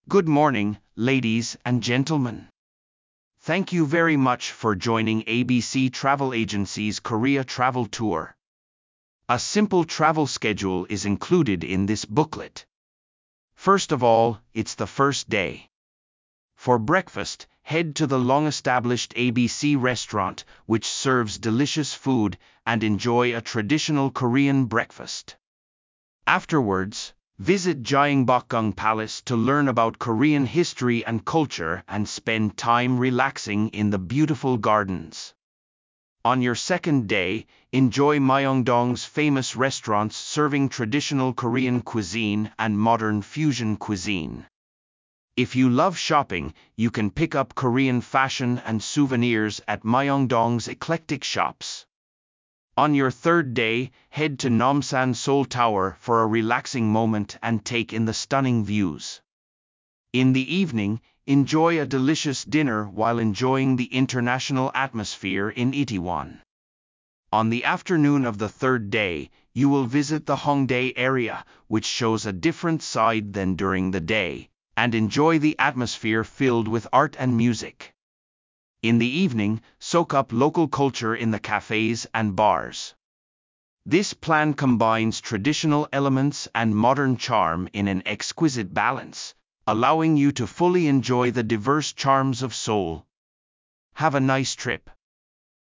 本文読み上げ